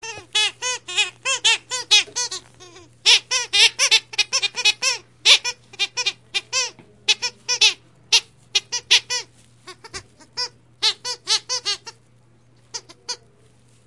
Звуки пищалки игрушки для собак
Звук игрушечной курицы для собаки